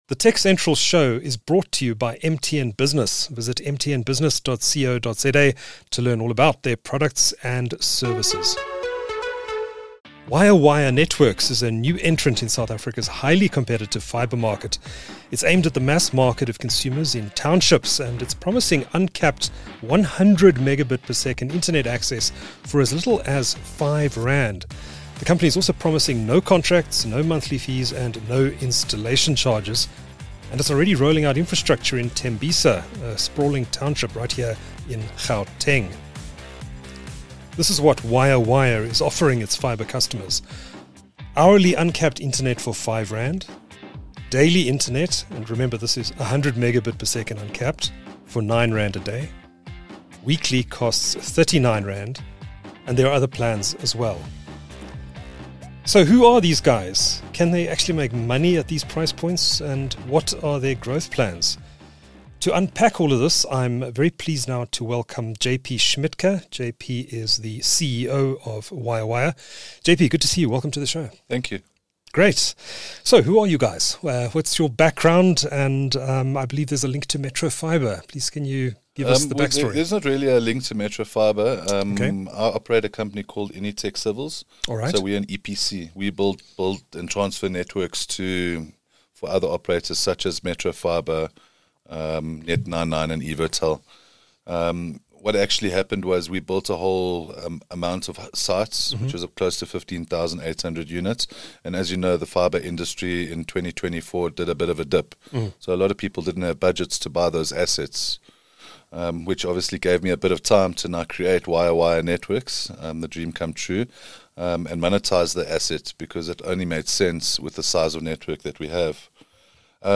The TechCentral Show (TCS, for short) is a tech show produced by South Africa's leading technology news platform. It features interviews with newsmakers, ICT industry leaders and other interesting people.